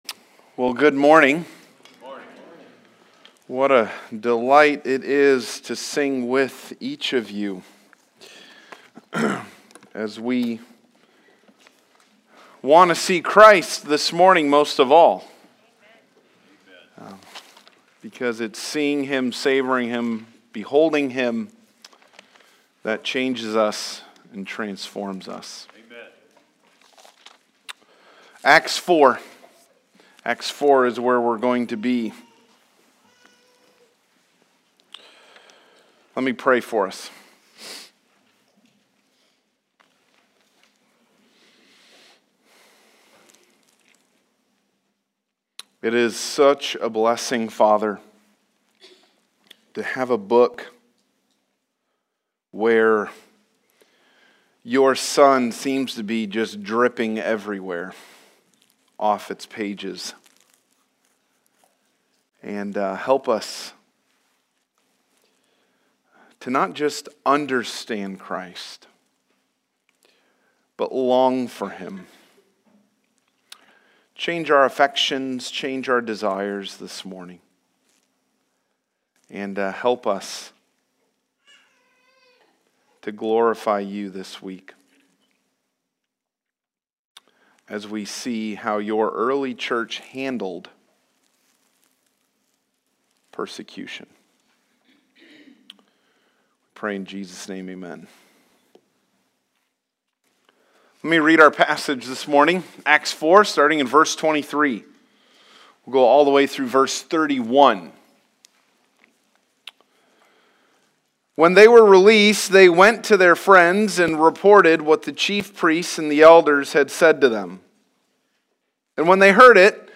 Passage: Acts 4:23-31 Service Type: Sunday Morning « Suffering and Boldness in the Early Church